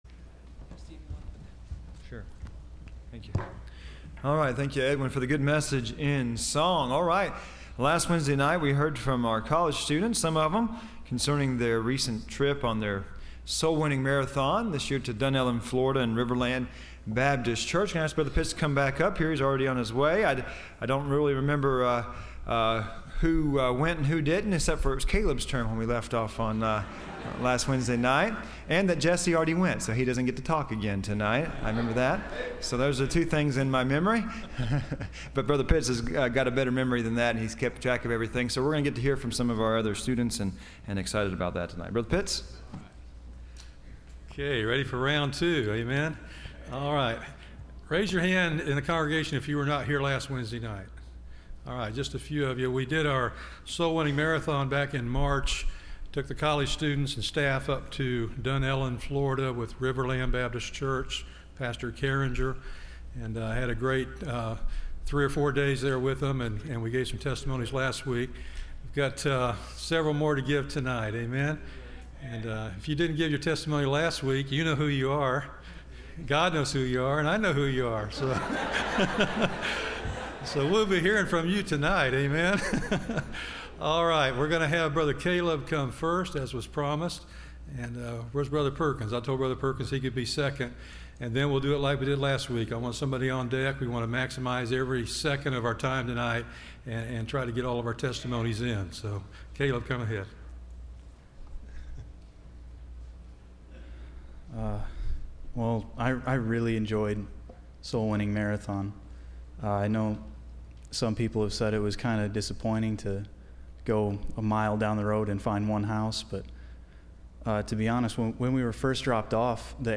Service Type: Wednesday College